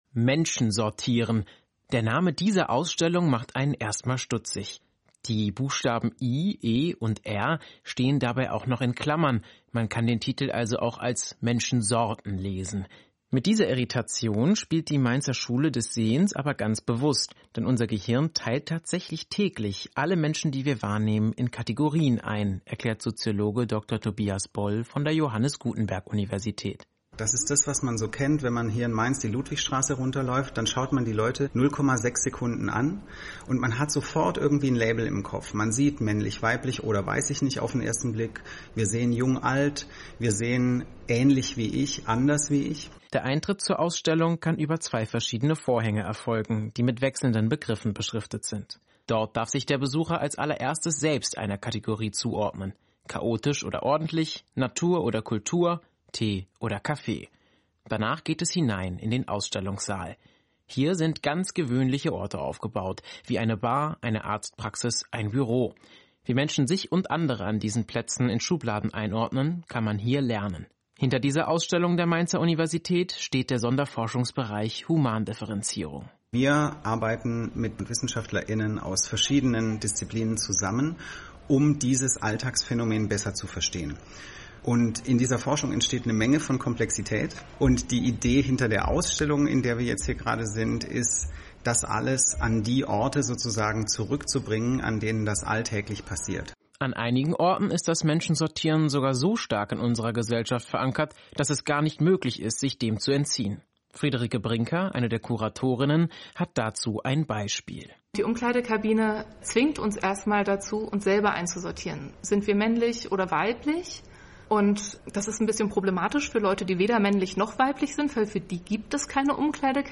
Audioflyer (German)
Radio feature by SWR Kultur